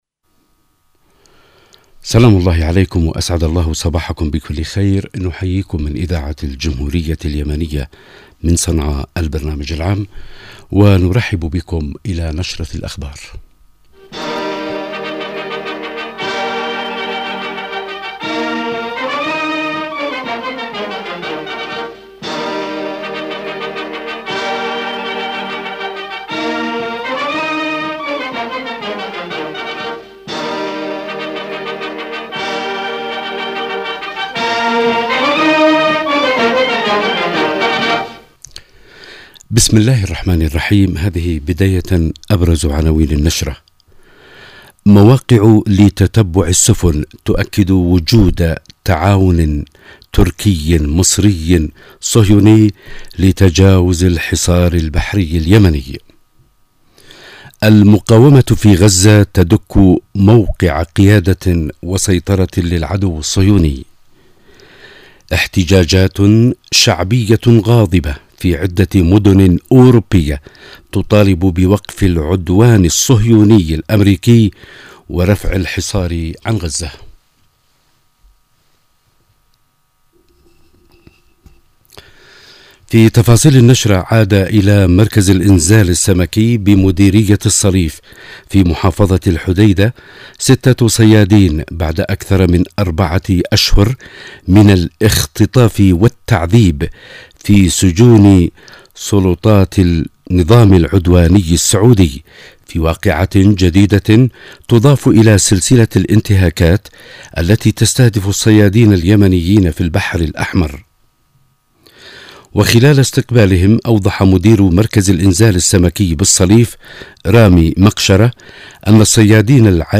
نشرة الحادية عشرة